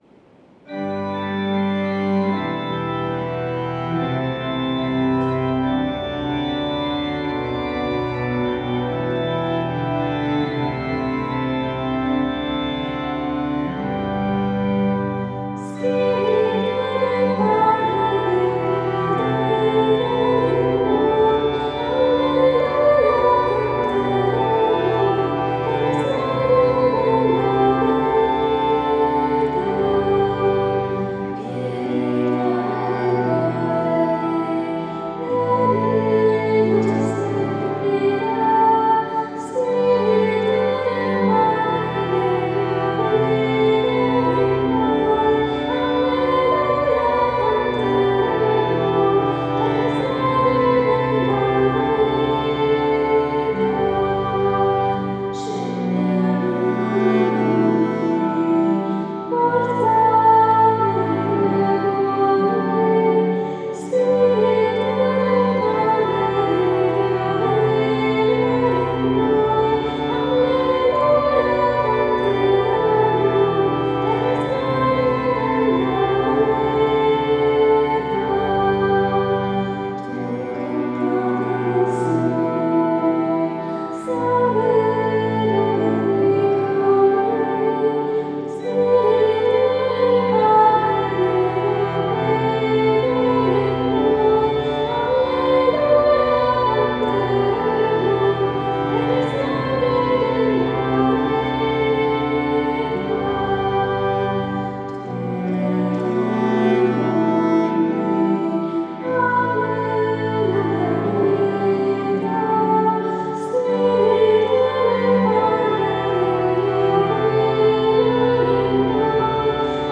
Canto di invocazione allo Spirito Santo che richiama la presenza vivificante dello Spirito nella vita dei credenti. La struttura musicale alterna strofe e ritornello con una melodia semplice e raccolta che favorisce il clima di preghiera.